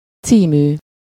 Ääntäminen
IPA: /ˈt͡siːmyː/